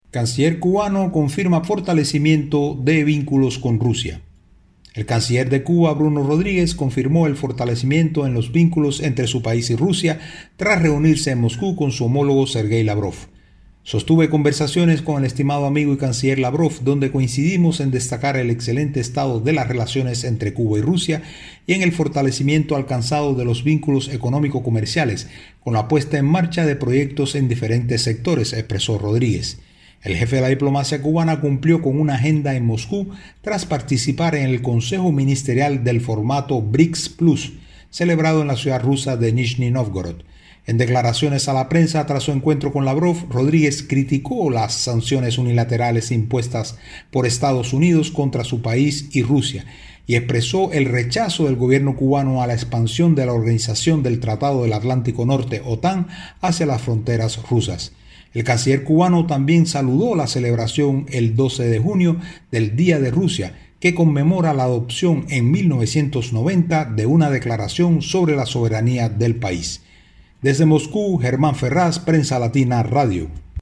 desde Moscú